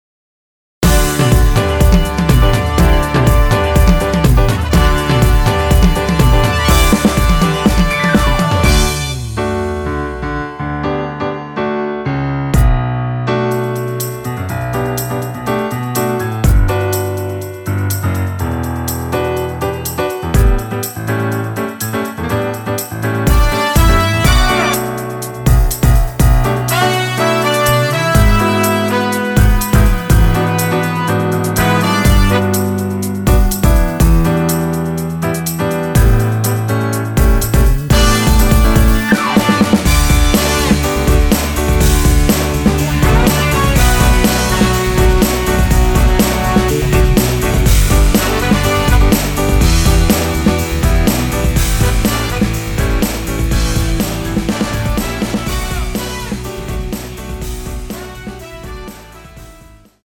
원키(1절+후렴)으로 진행되는 MR입니다.
앞부분30초, 뒷부분30초씩 편집해서 올려 드리고 있습니다.
중간에 음이 끈어지고 다시 나오는 이유는